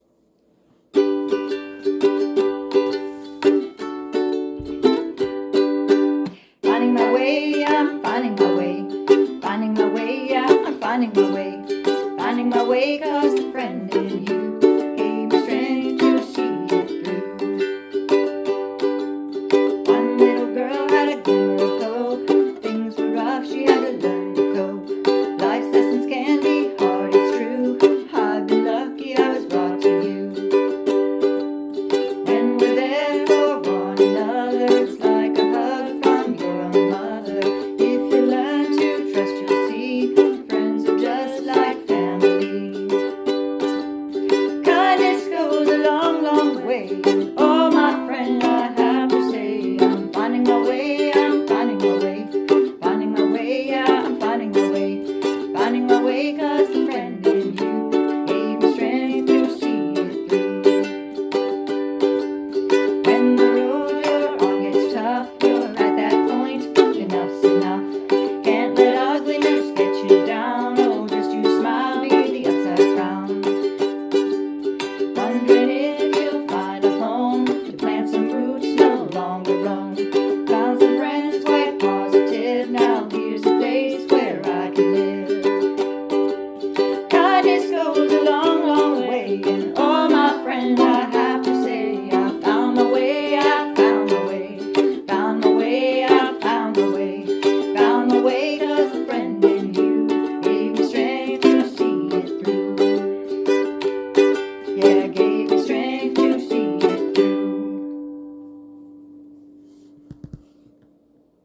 (G)